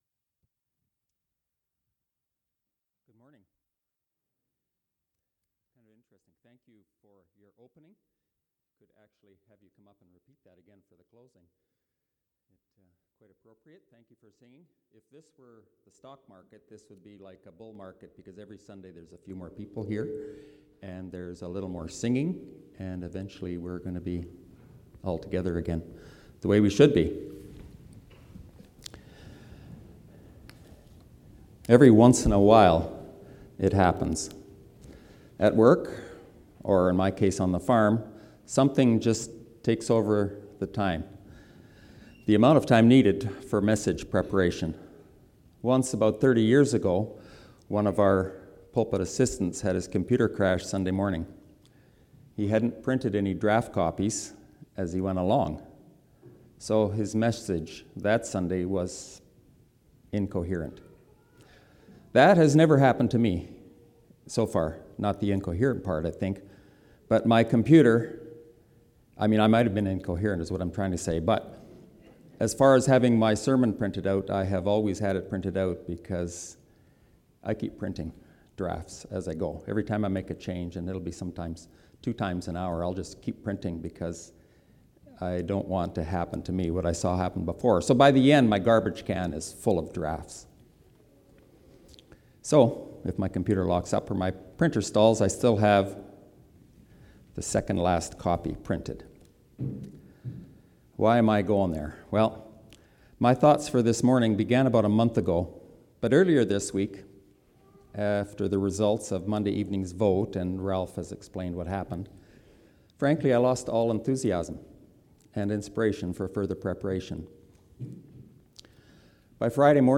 Sermons - Rosemary Mennonite Church